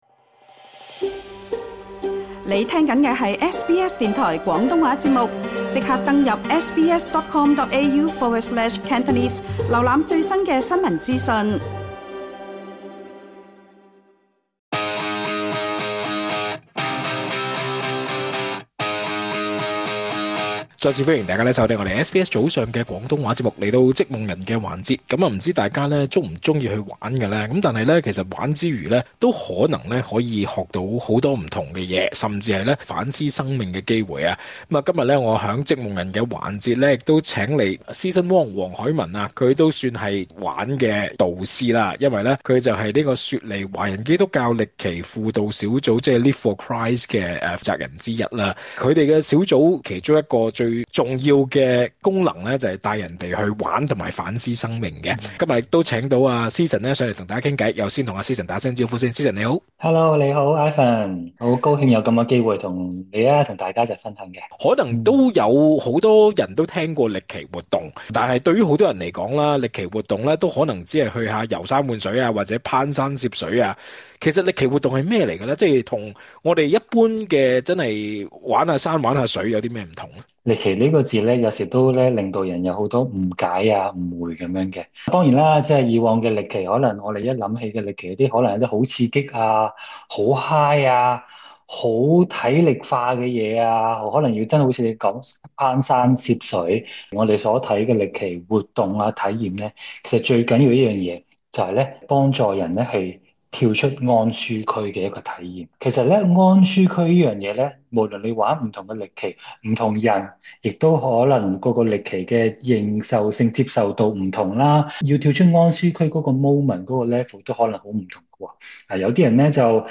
更多詳情，請收聽完整訪問錄音。